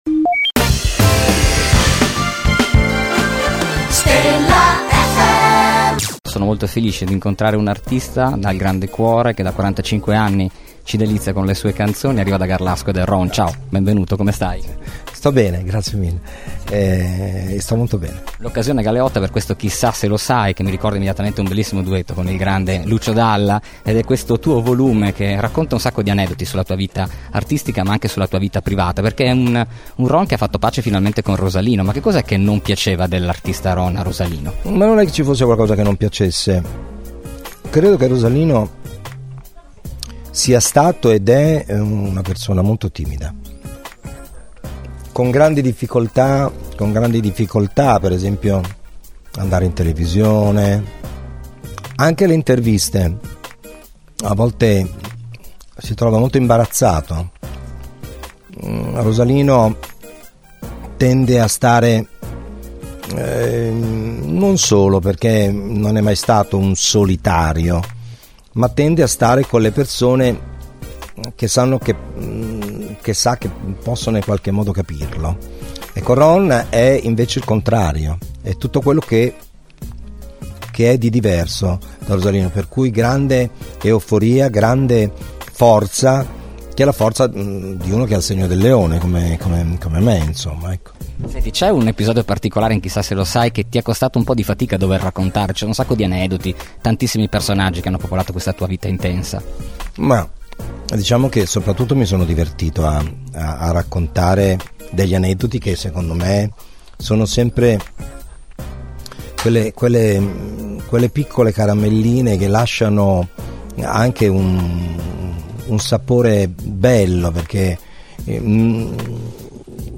Intervista Ron | Stella FM
Intervista-Ron.mp3